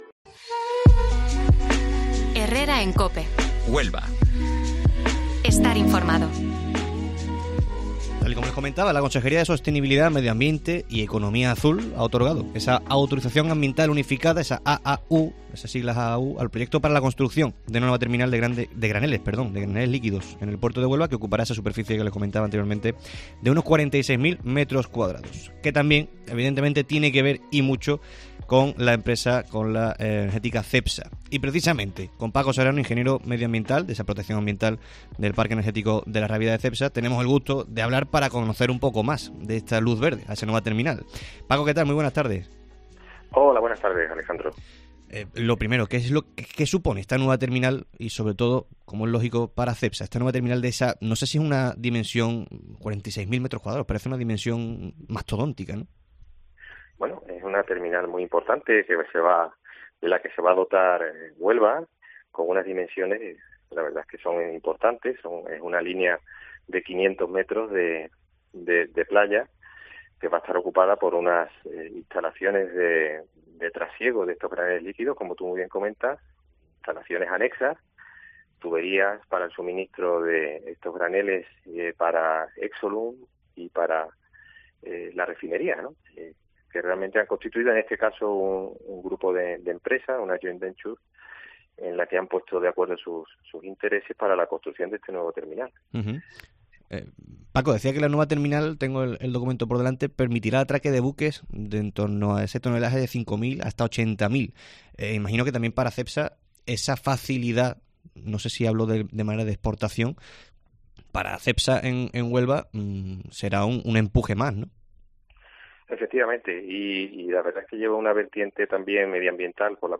hemos hablado en el Herrera en COPE Huelva de este jueves.